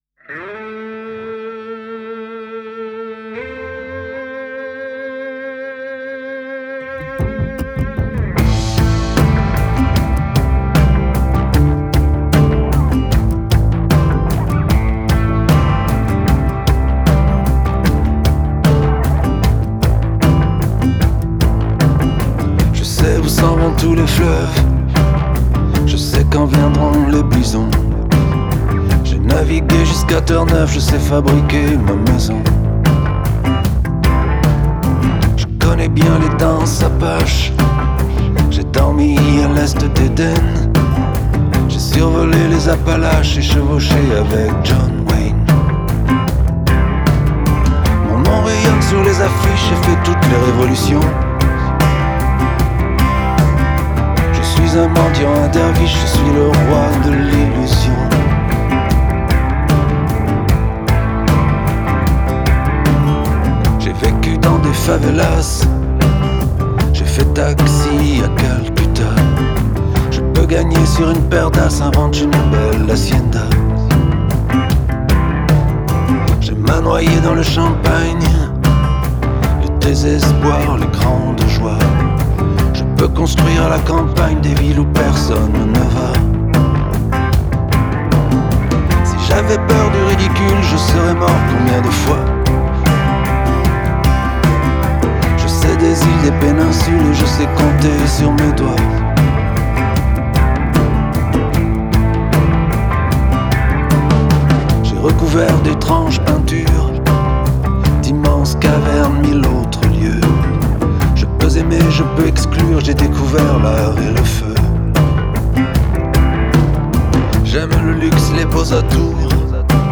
trompette